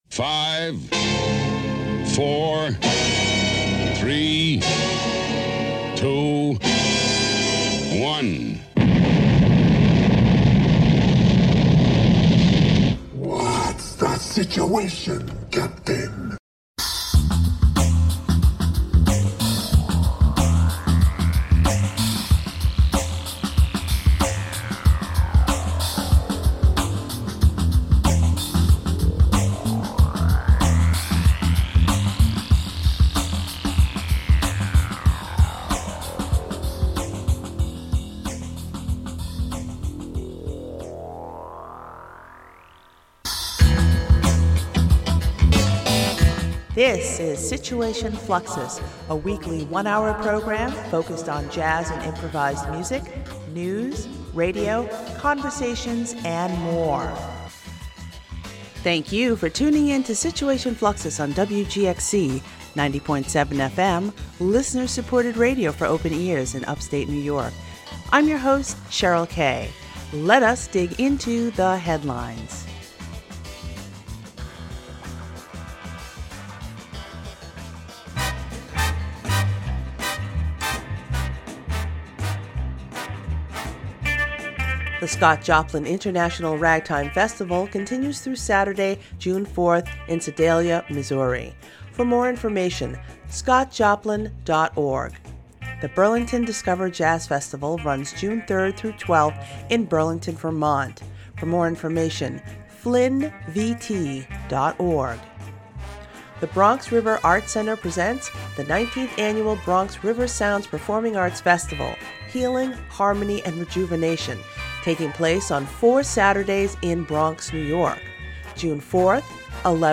Situation Fluxus is a weekly one-hour program focused on jazz and improvised music, news, radio, conversations, and more.